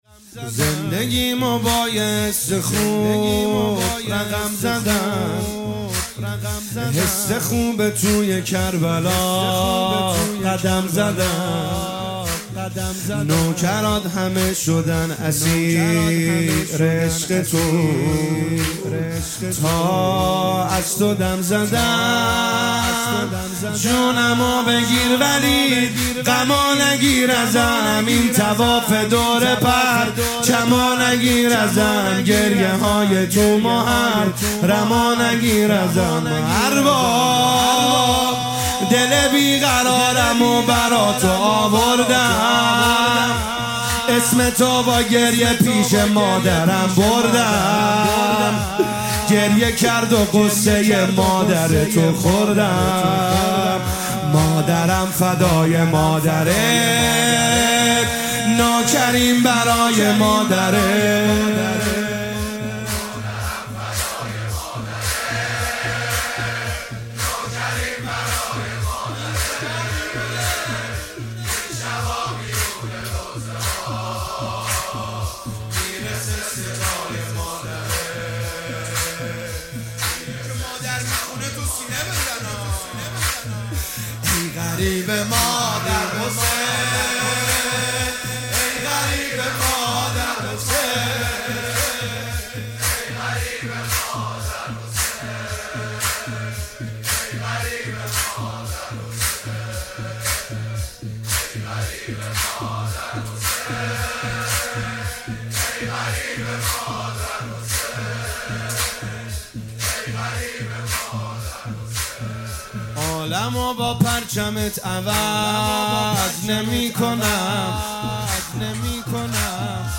مداحی شب زیارتی کربلا